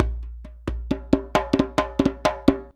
089DJEMB04.wav